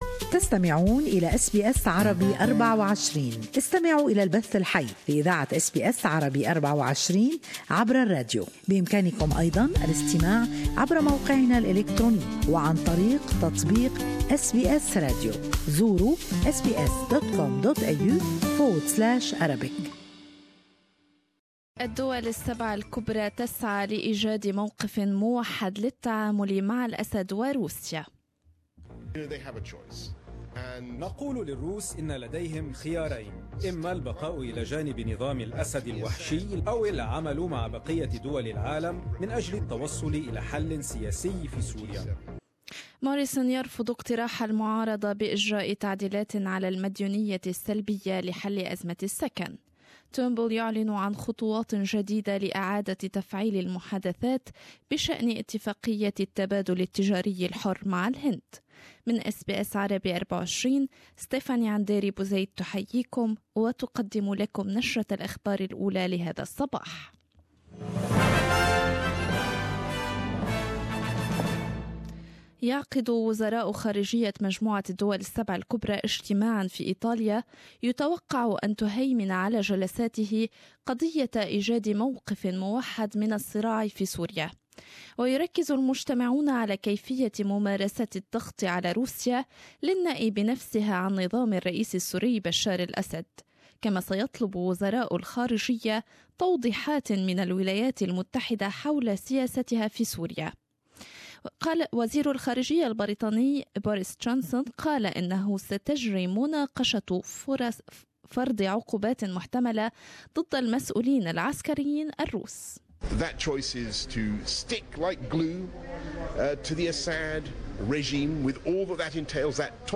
In this bulletin ...